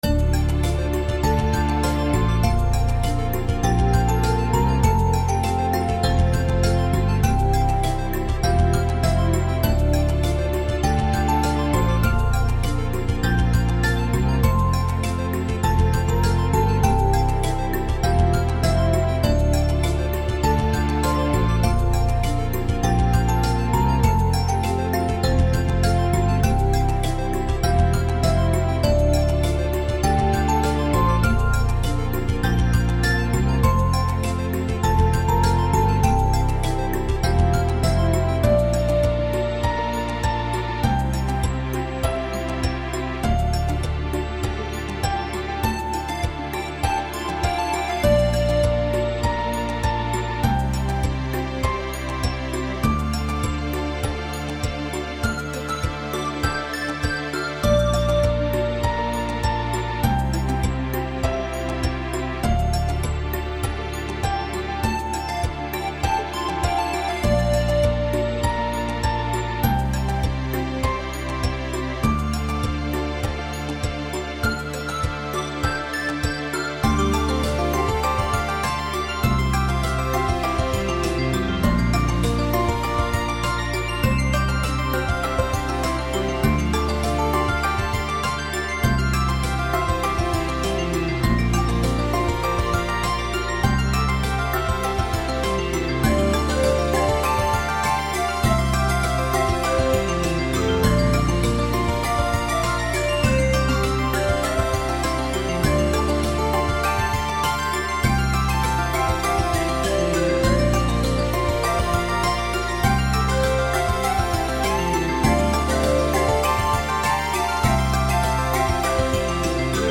Artist Full Track commission for anonymous I think this may be my new favorite relaxing track. I always love doing these chill lo-fi tunes that you can just relax and meditate to.
This was very relaxing.